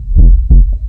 warning_heartbeat.ogg